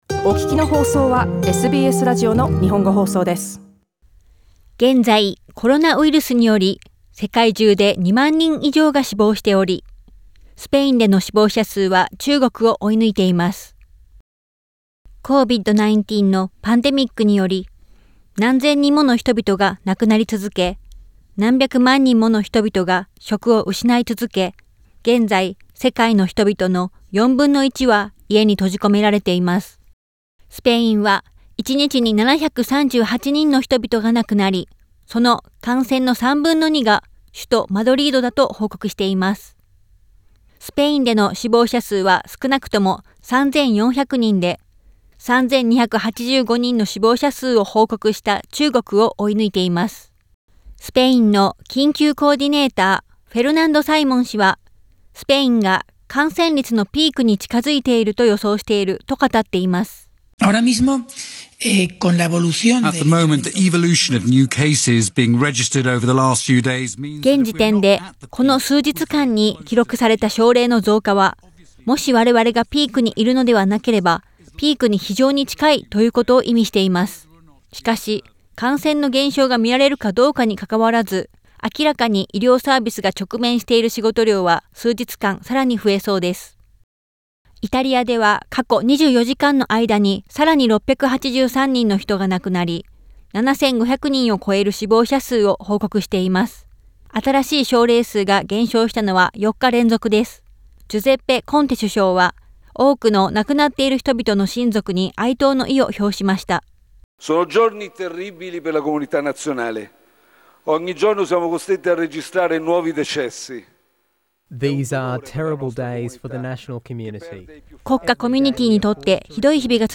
コロナウイルスをめぐる各国の状況についての26日のリポートです。